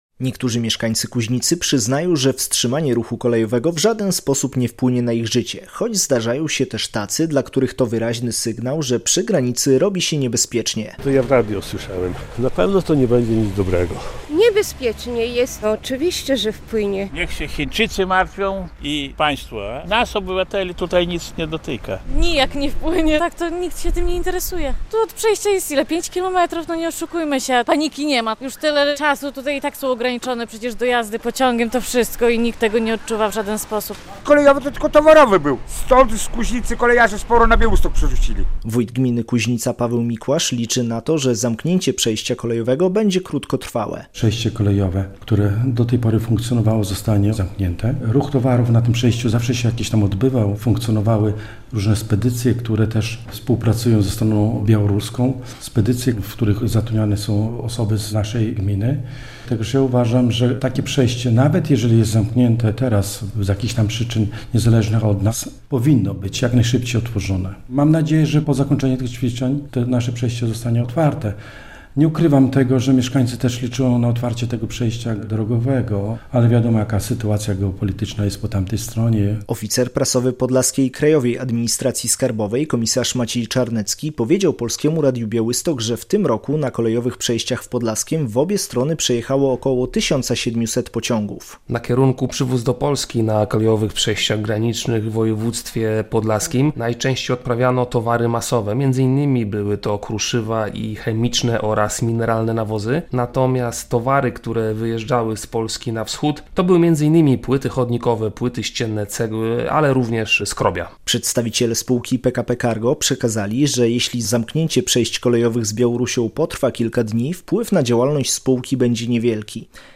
Mieszkańcy Kuźnicy o zamknięciu przejść - relacja